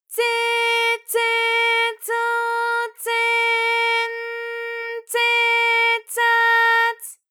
ALYS-DB-001-JPN - First Japanese UTAU vocal library of ALYS.
tse_tse_tso_tse_n_tse_tsa_ts.wav